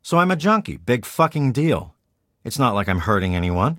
Category:Fallout 3 audio dialogues Du kannst diese Datei nicht überschreiben.